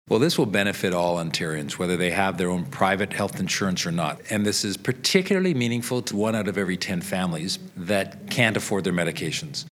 Recording Location: toronto
Type: News Reports